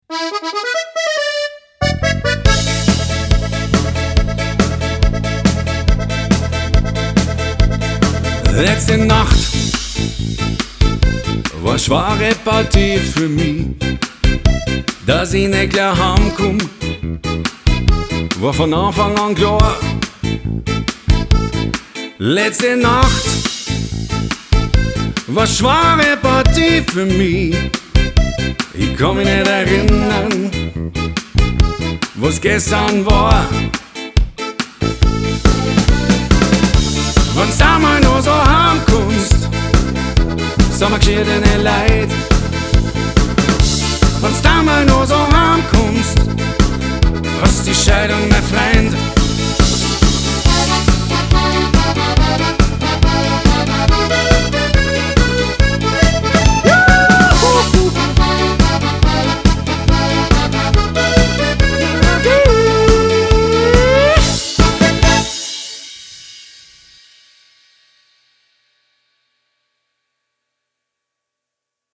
Lead-Gesang, Saxophon, Akkordeon
Bass
Drums